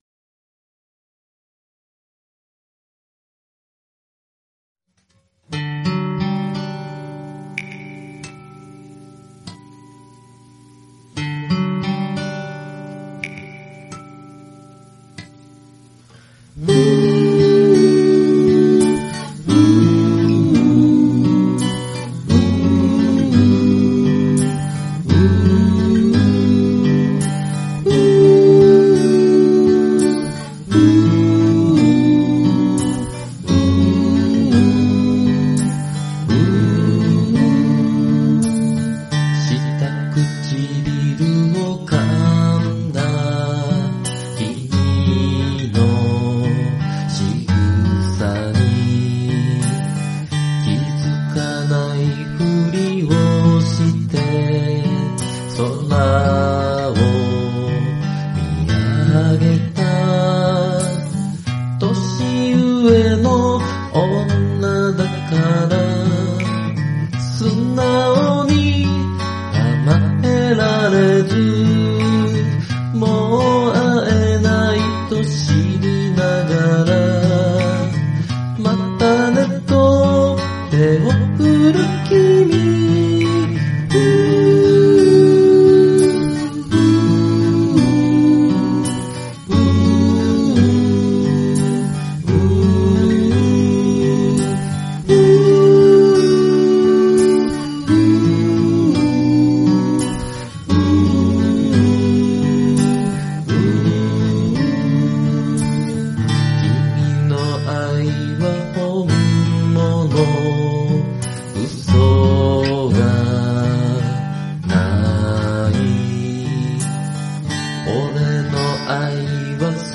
Vocal、Chorus、A.guitar
美しい仕上がりになったと思います。